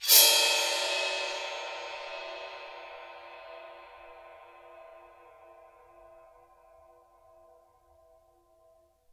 Percussion
susCymb1-scrape2_v1.wav